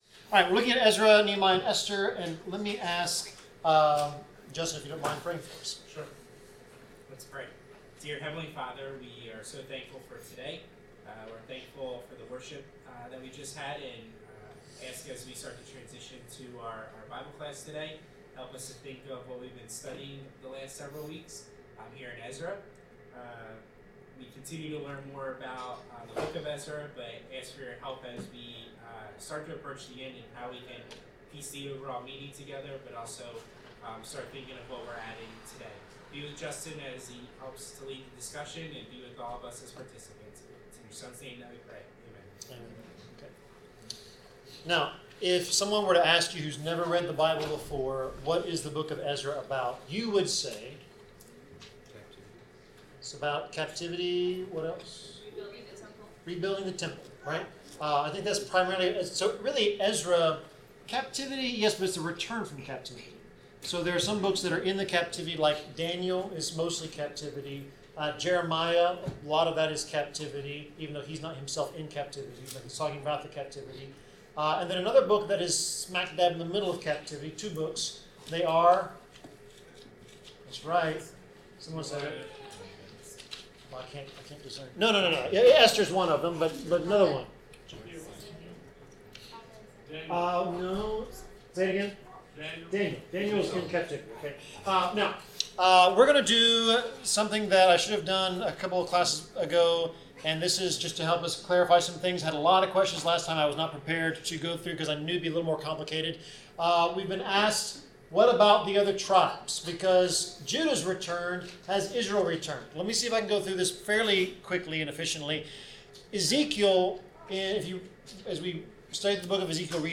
Bible class: Ezra 7-8
Passage: Ezra 4, Ezra 7-8 Service Type: Bible Class